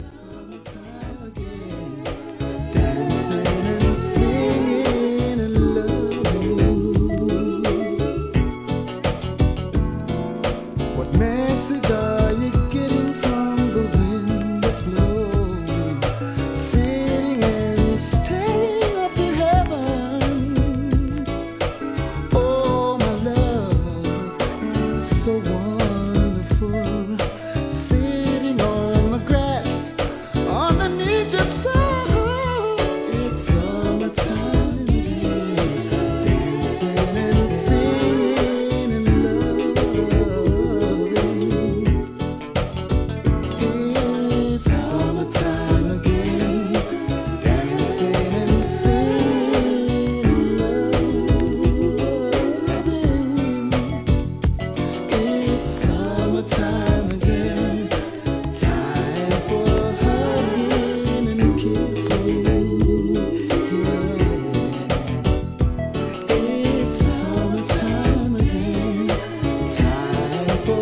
B (中盤で数週ノイズ、SAMPLEご確認ください)
REGGAE
R&B調、好マイナー物♪